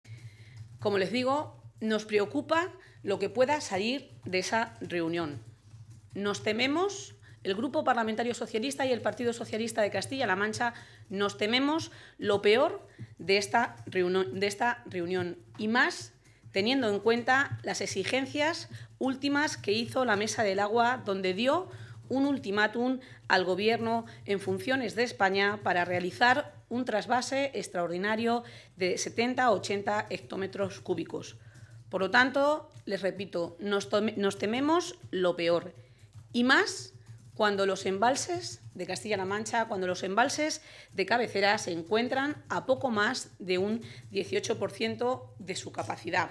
La diputada del Grupo socialista en las Cortes de Castilla-La Mancha, Agustina García, ha advertido que la reunión que van a mantener hoy la ministra de Agricultura, Isabel García-Tejerina, con el presidente de Murcia y con representantes de los regantes de esta comunidad puede traer muy malas noticias para el Tajo.
Cortes de audio de la rueda de prensa